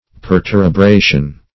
Search Result for " perterebration" : The Collaborative International Dictionary of English v.0.48: Perterebration \Per*ter`e*bra"tion\, n. [L. perterebratus, p. p. of perterebrare to bore through.] The act of boring through.